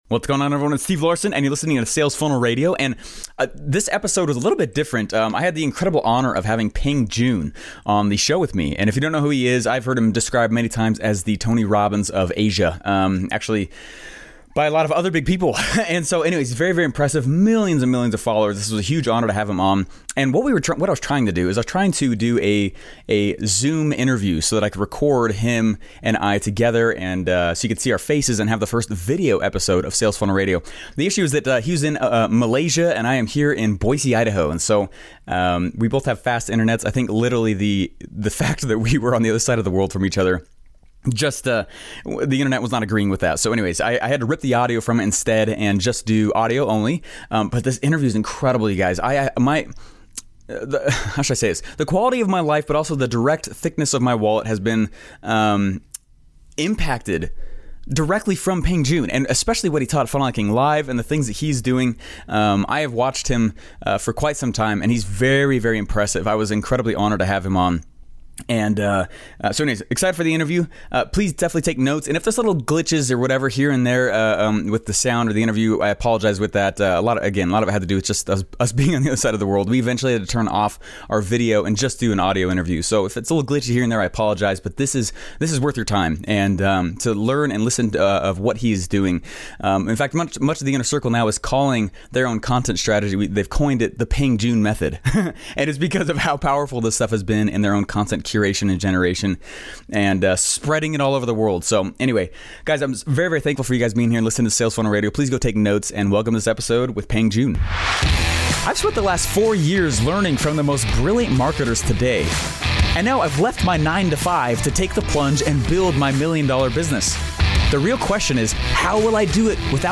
So anyways, I had ripped the audio from it instead and just do audio only.
We eventually had to turn off our video, and just do an audio interview.